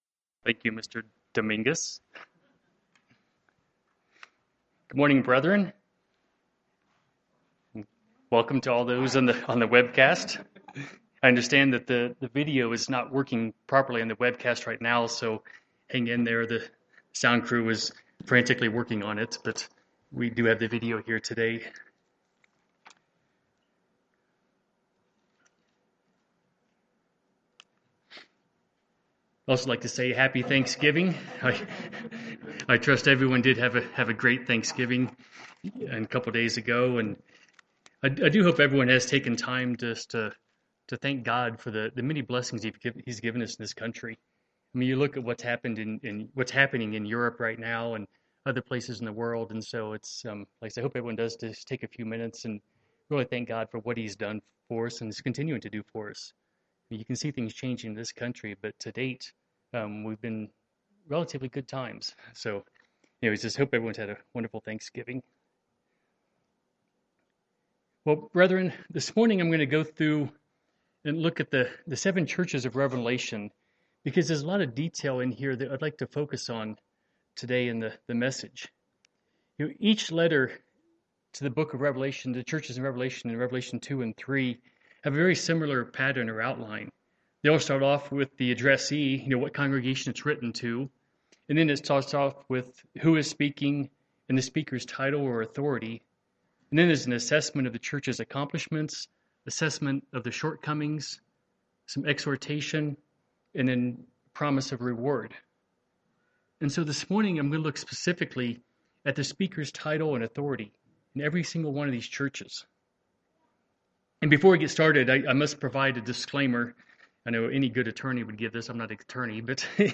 In each letter, Christ is described with a different title/authority. This sermon will look at Jesus Christ's title/authority used in each letter and examine why He uses that title with the particular church.